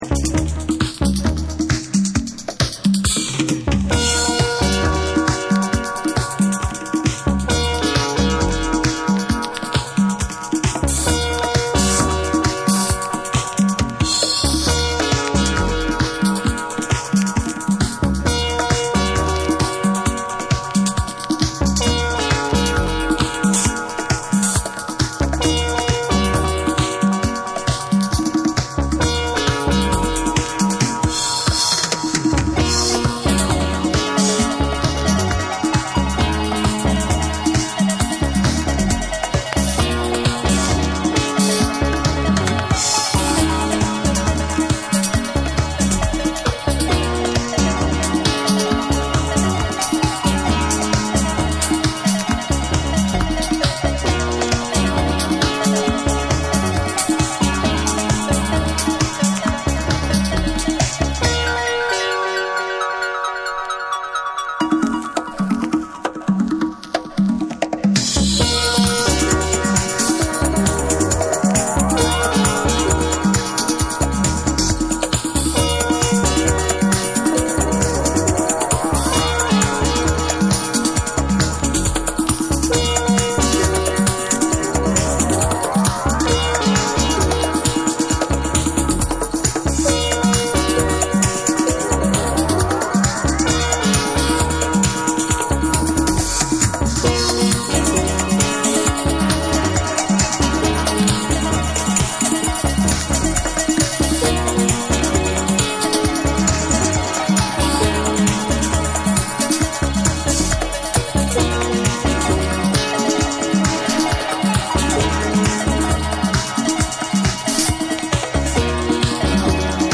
DANCE CLASSICS / DISCO